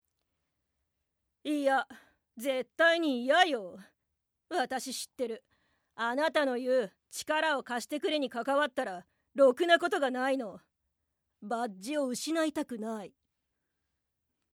外画　３０代女性警察官